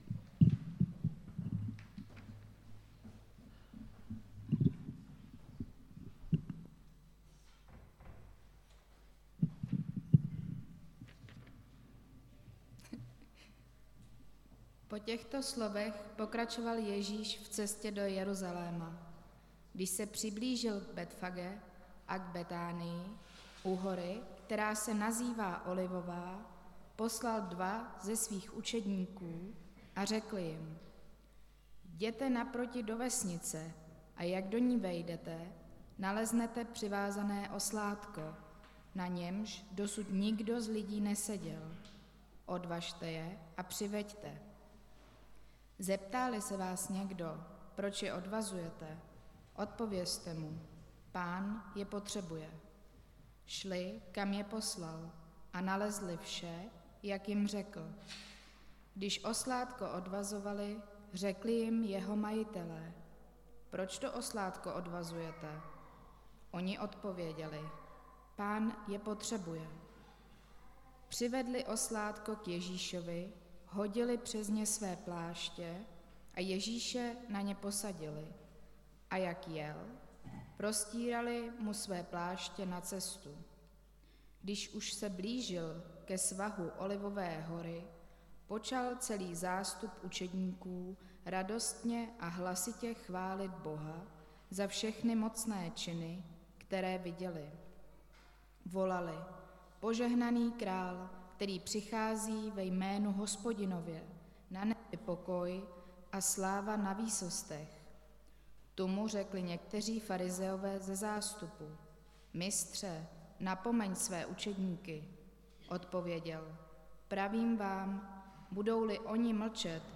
Nedělní kázání – 11.12.2022 Předvánoční úklid